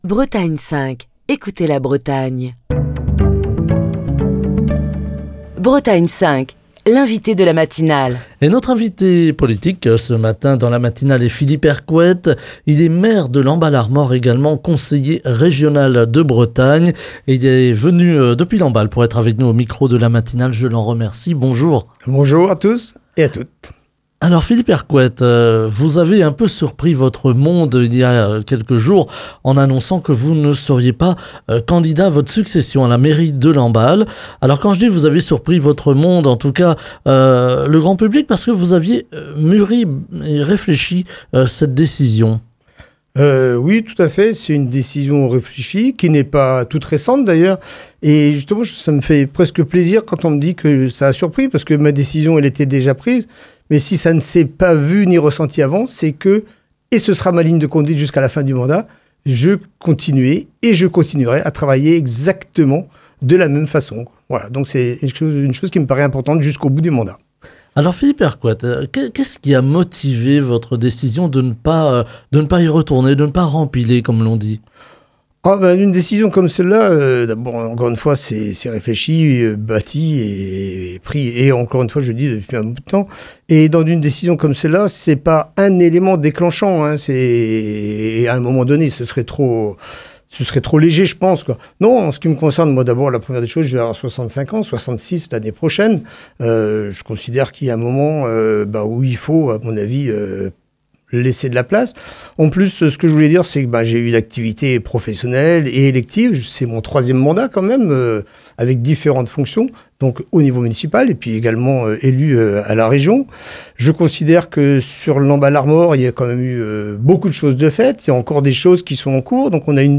Ce mercredi, Philippe Hercouët, maire de Lamballe-Armor, conseiller régional de Bretagne était l'invité politique de Bretagne 5 Matin.